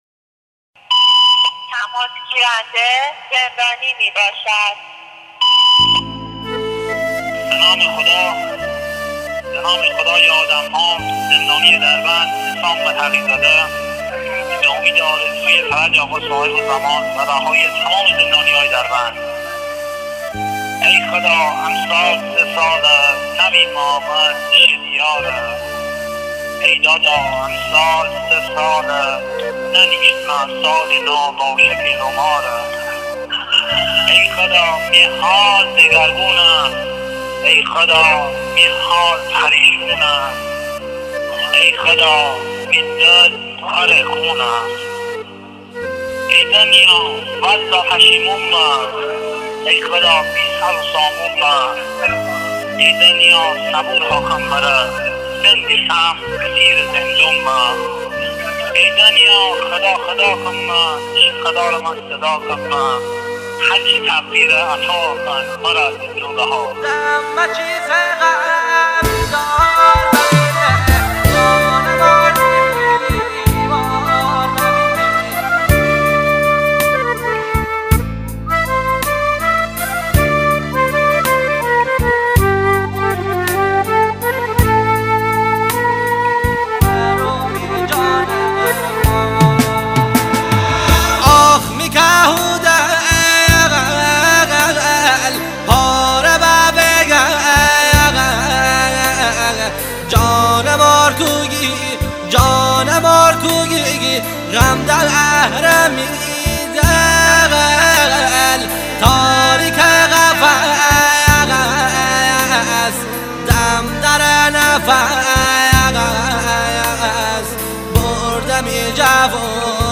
آهنگ مازندرانی
آهنگ غمگین